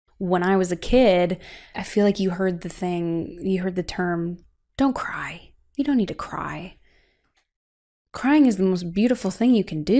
woman.wav